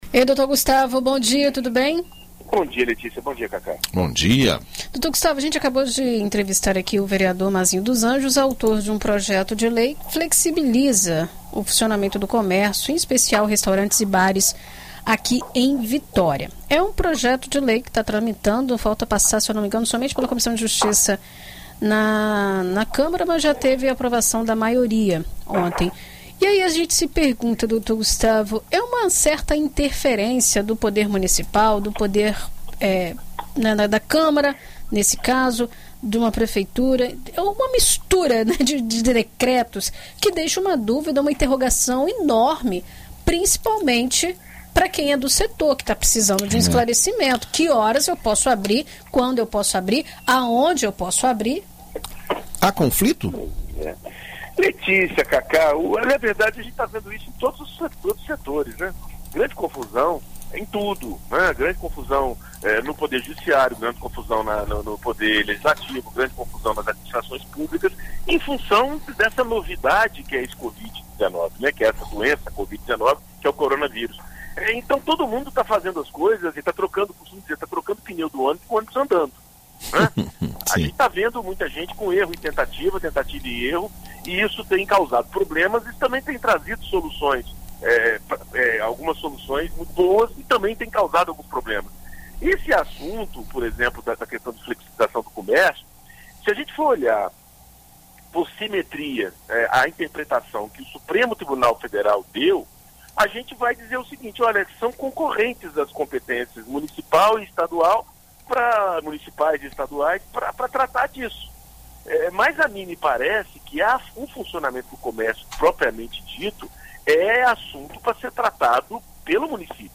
Na BandNews FM